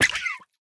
Minecraft Version Minecraft Version 1.21.4 Latest Release | Latest Snapshot 1.21.4 / assets / minecraft / sounds / mob / axolotl / death2.ogg Compare With Compare With Latest Release | Latest Snapshot
death2.ogg